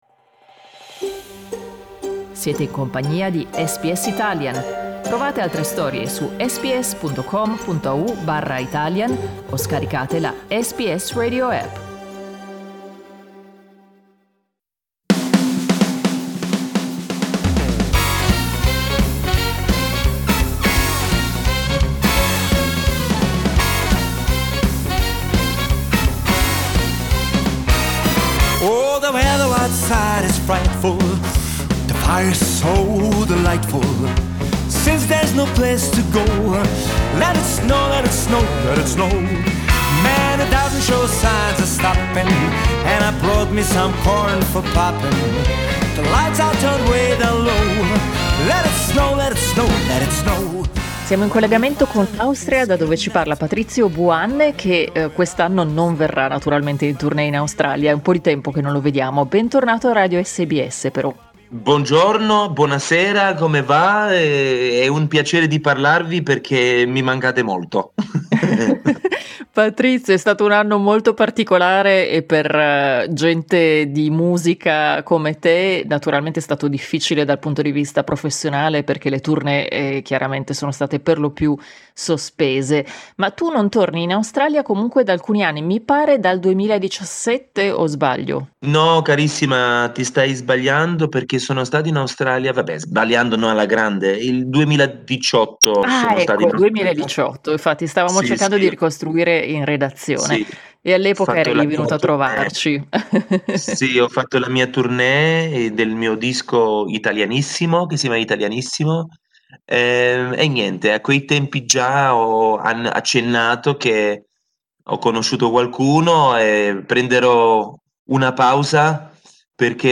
Ascolta l'intervista: LISTEN TO Patrizio Buanne e il suo nuovo disco per un Natale "internazionale" SBS Italian 12:02 Italian Le persone in Australia devono stare ad almeno 1,5 metri di distanza dagli altri.